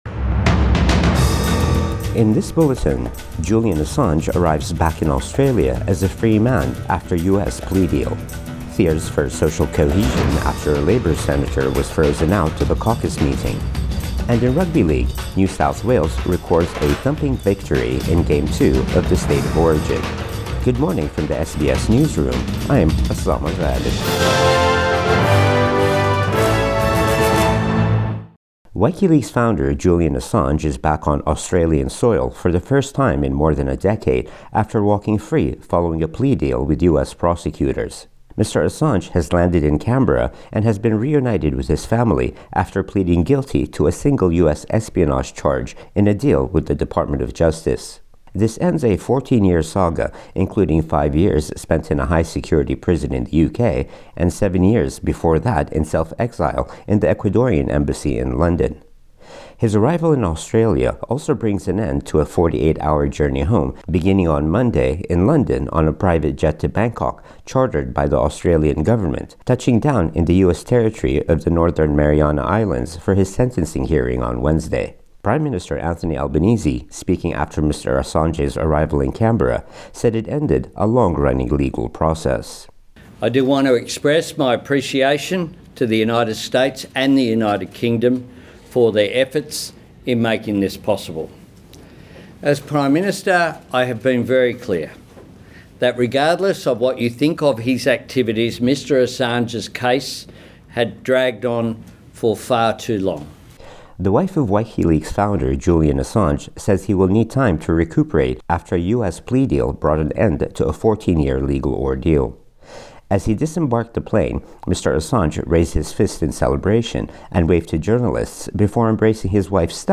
Morning News Bulletin 27 June 2024